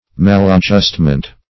Maladjustment \Mal`ad*just"ment\, n. [Mal- + adjustment.]